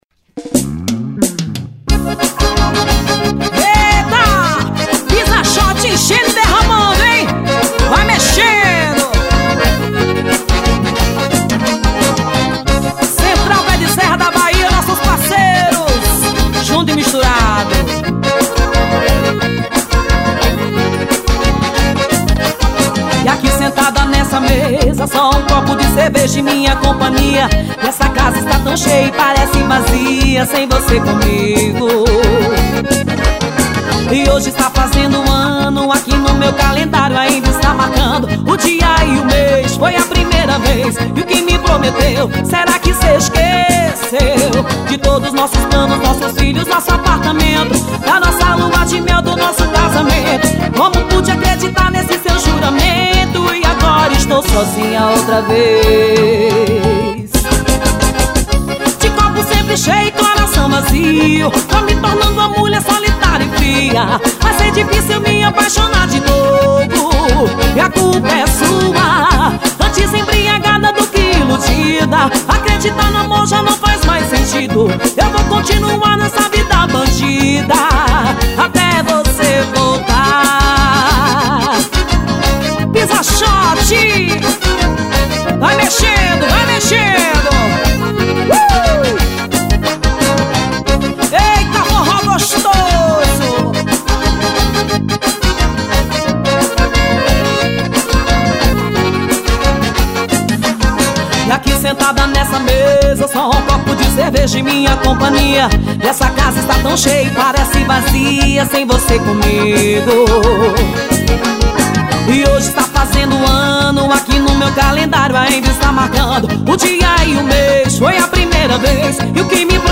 Composição: forro.